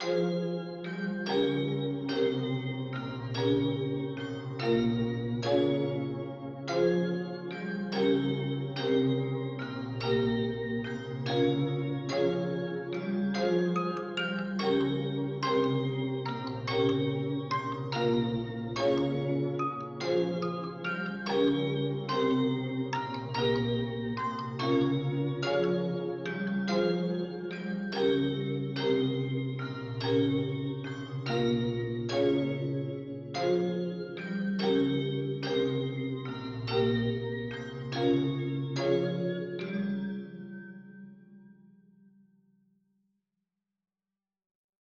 144BPM, C Minor